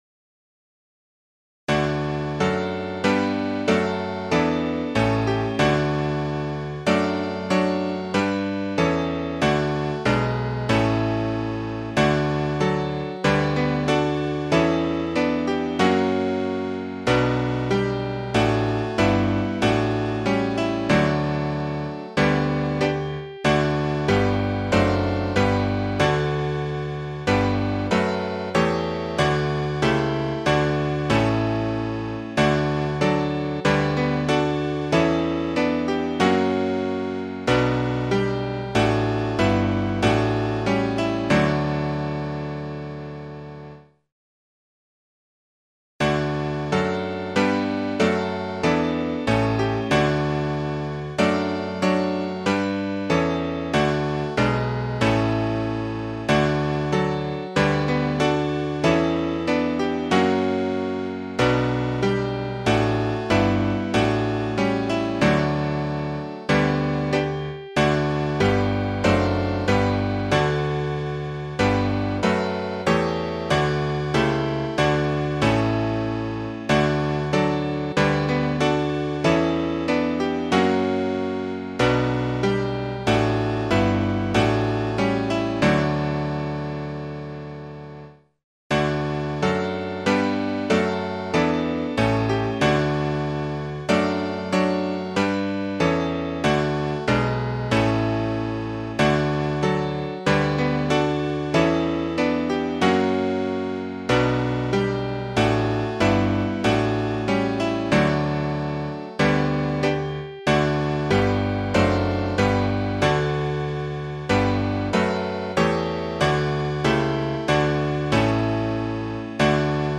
/ Métrica: 7.7.7.7.D / Ideas o keywords: Salvación.